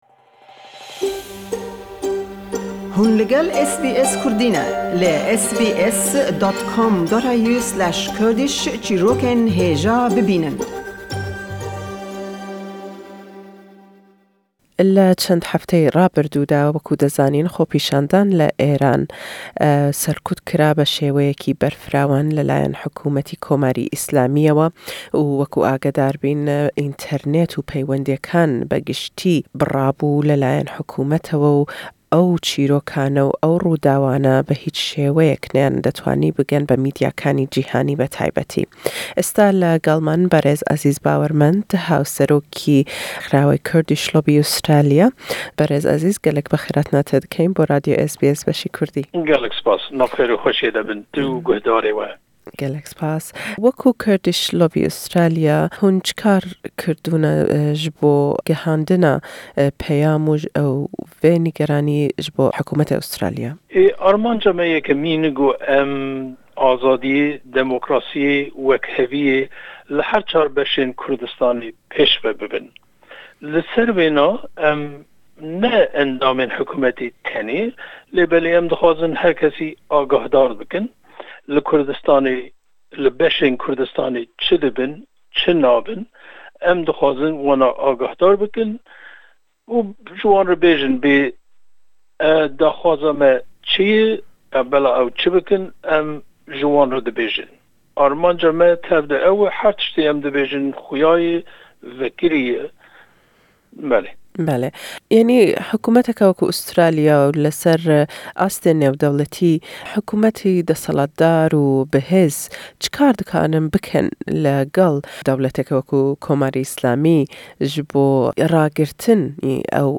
Le em hevpeyvîne da